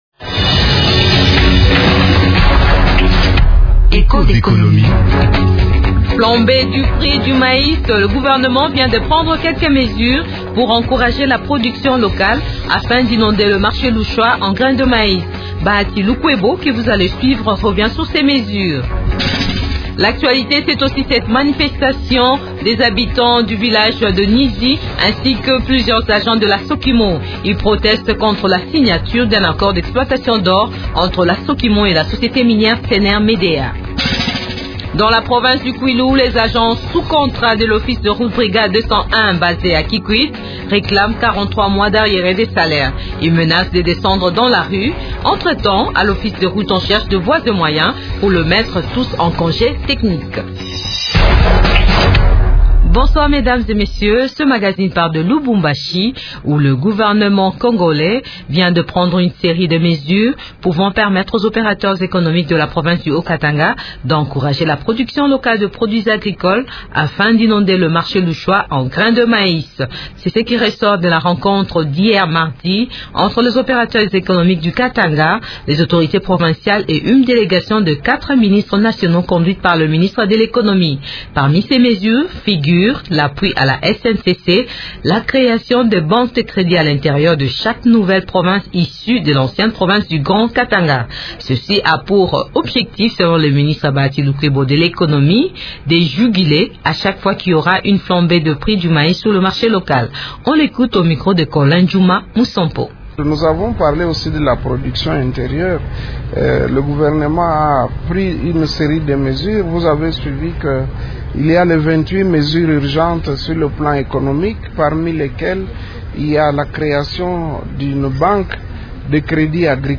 Ce magazine revient aussi sur la marche des agents de la Société minière de Kilomoto (Sokimo) et les habitants de la localité de Nizi, à 30 kilomètres au nord de Bunia (Ituri).